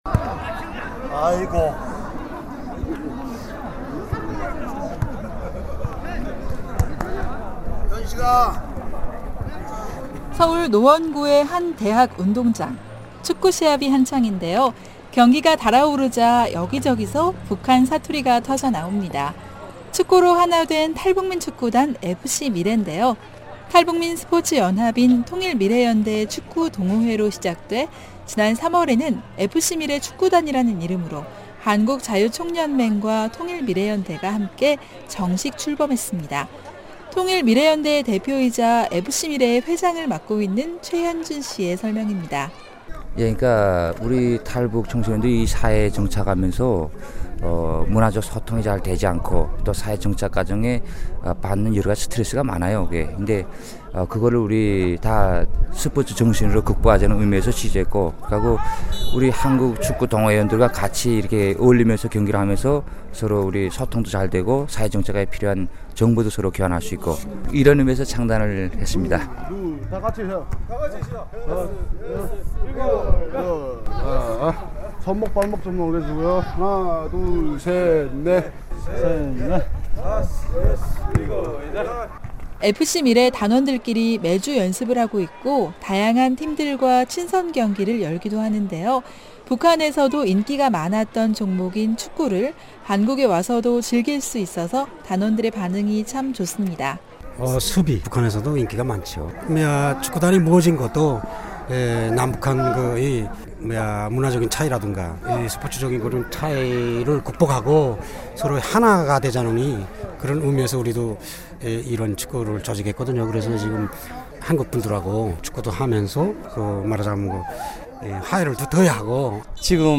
기자가 전해드립니다.